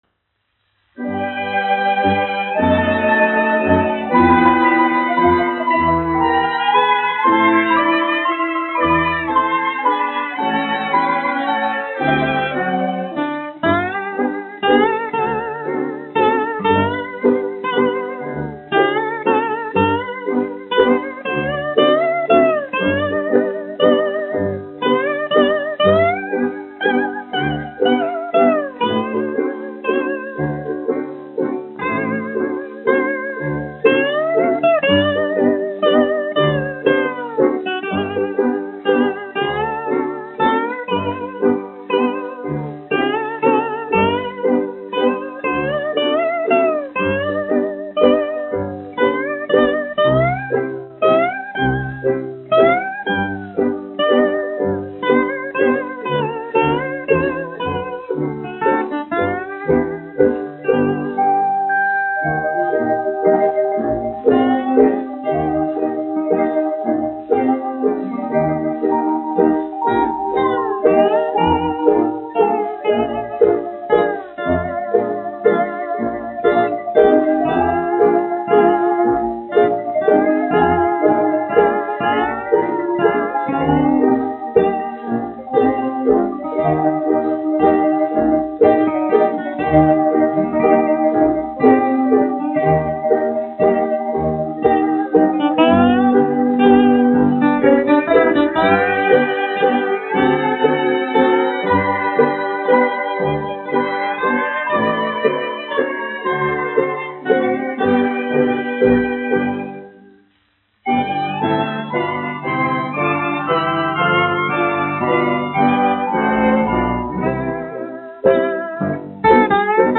1 skpl. : analogs, 78 apgr/min, mono ; 25 cm
Populārā instrumentālā mūzika
Skaņuplate